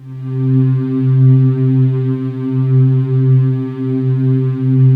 Index of /90_sSampleCDs/USB Soundscan vol.28 - Choir Acoustic & Synth [AKAI] 1CD/Partition D/11-VOICING